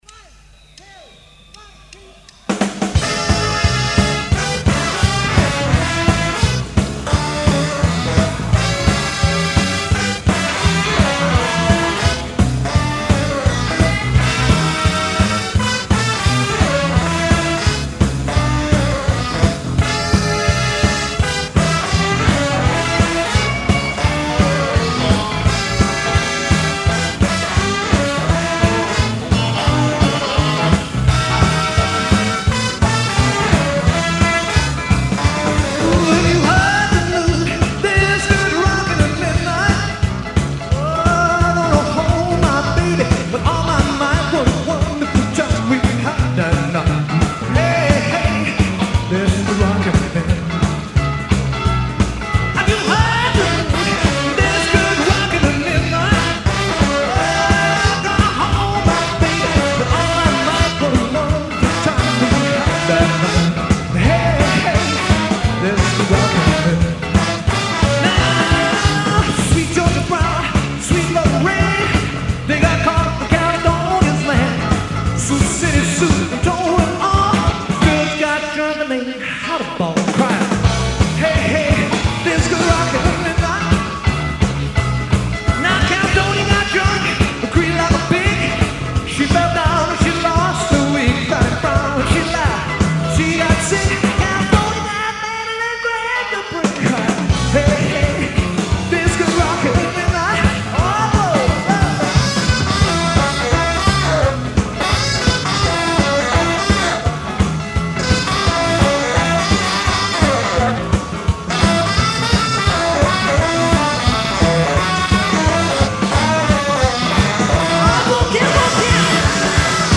toe-tappin’, finger snappin’
in concert in Birmingham England in September 1985